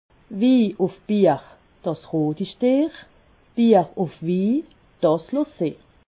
Haut Rhin
Ville Prononciation 68
Ribeauvillé